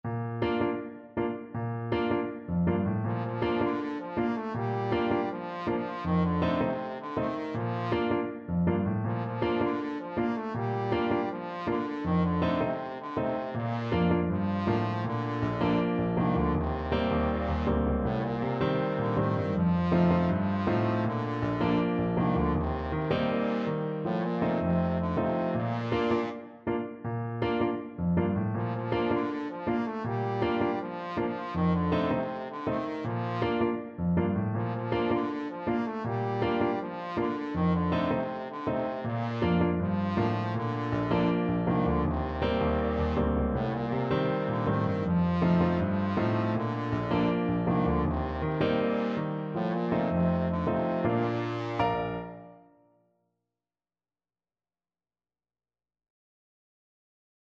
4/4 (View more 4/4 Music)
Fast, reggae feel =c.160 =160
A3-Bb4
Traditional (View more Traditional Trombone Music)
world (View more world Trombone Music)
Caribbean Music for Trombone